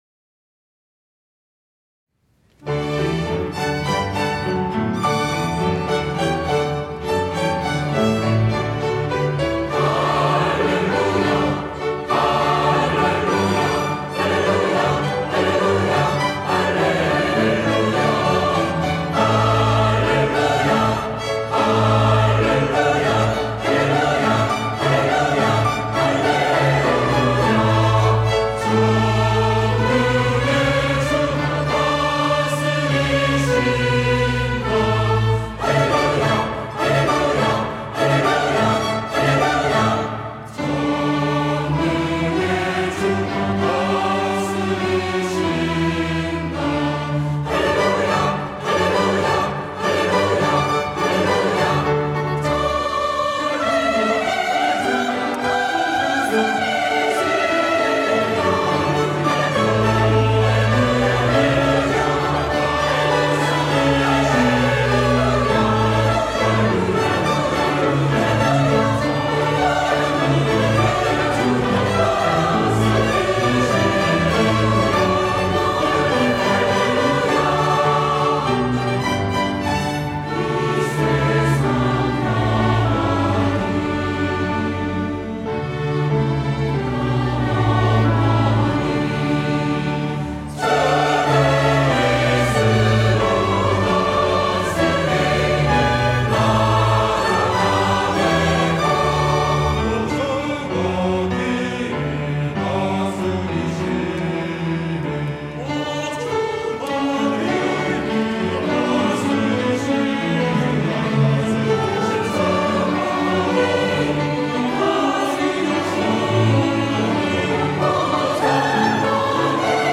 호산나(주일3부) - 할렐루야
찬양대